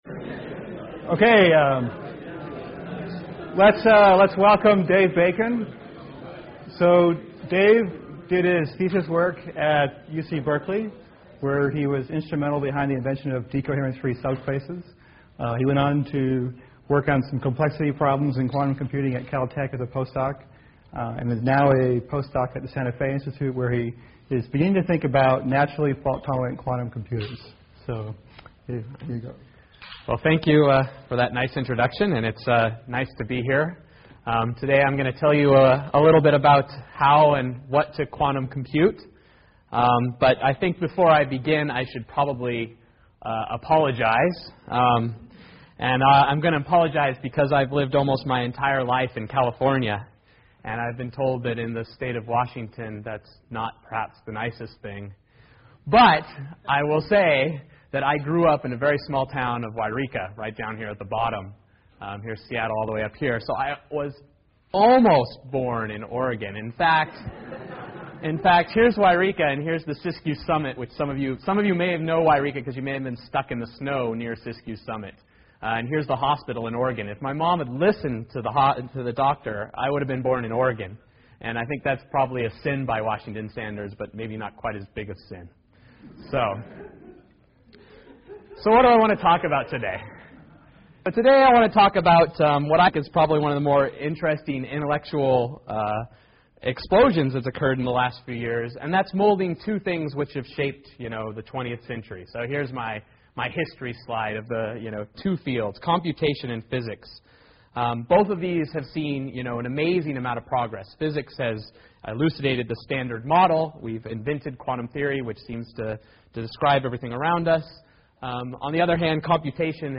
Colloquium Thursday, February 24, 2005, 3:30 pm EE-105 Abstract Quantum computing represents the synthesis of fundamental quantum physics with concepts from computer science.